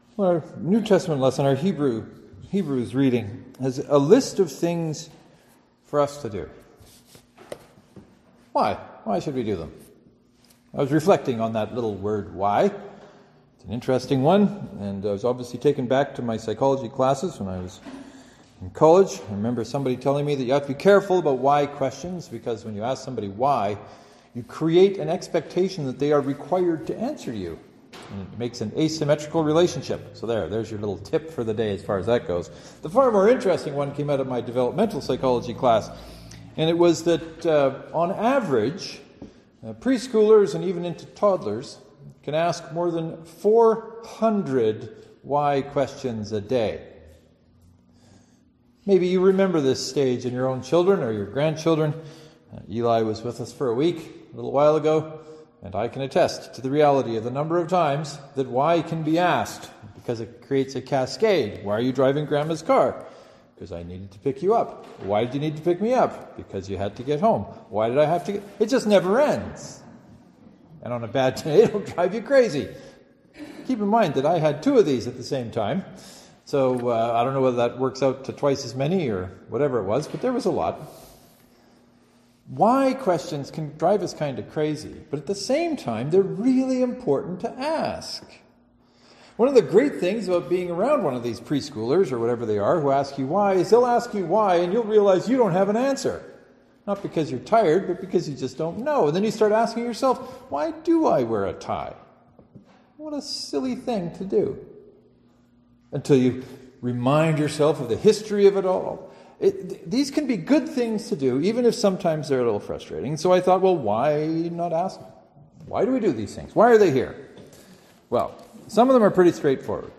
Our Hebrews passage for this Sunday has a list of things we should do: be hospitable, be empathetic, be faithful, be content, and more . . . but why? The sermon title for this Sunday was “Why do these things?”